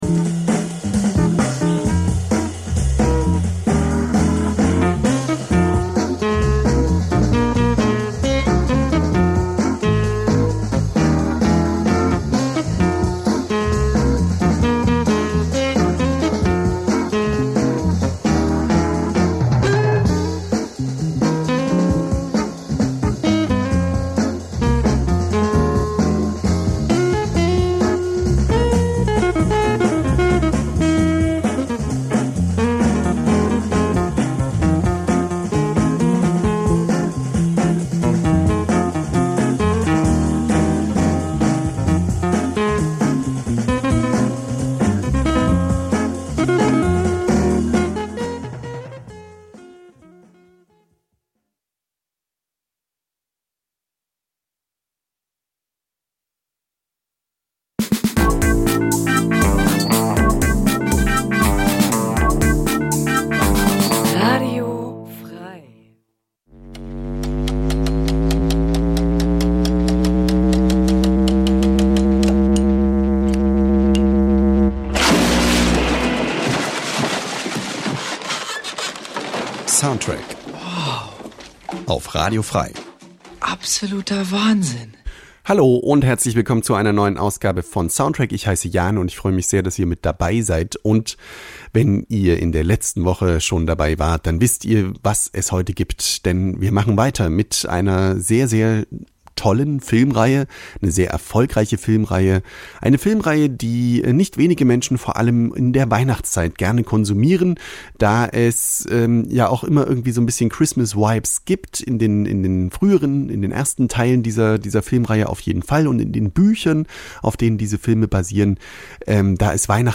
Wöchentlich präsentieren wir ausgesuchte Filmmusik.
Filmmusik Dein Browser kann kein HTML5-Audio.